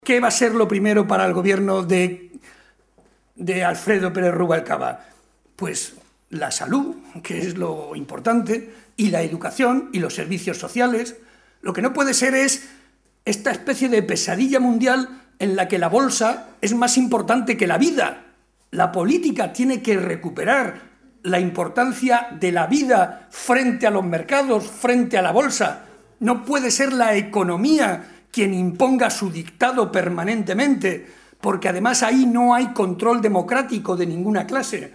Barreda argumentó que la realidad es más complicada que las “falsas expectativas” que lanzan los responsables políticos del PP, y para comprobarlo, señaló que tan sólo hay que ver los casos de Portugal, Castilla-La Mancha o Villarta de San Juan, localidad en la que intervino en un acto público, donde se han producido esos relevos de Gobierno y “las cosas, lejos de mejorar, han ido a peo”, demostrando que la realidad es mucho más tozuda y no van a conseguir, ni mucho menos, mejorar la situación”.